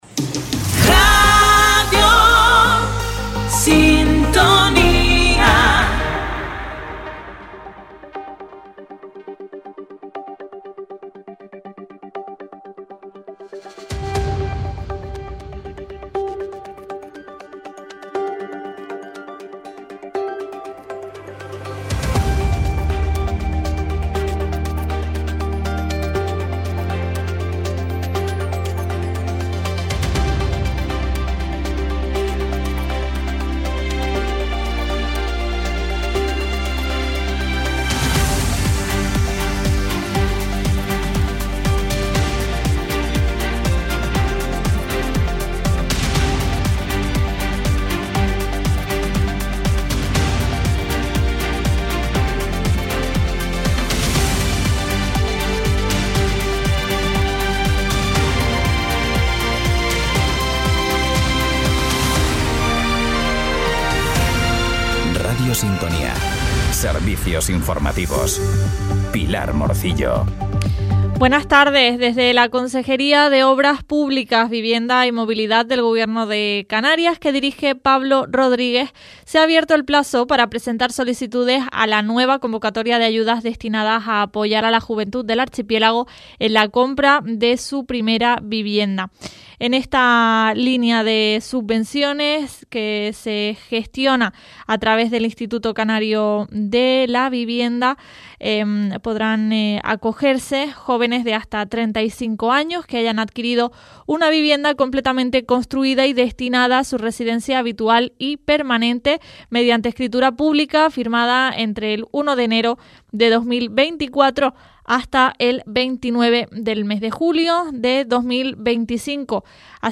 Servicios Informativos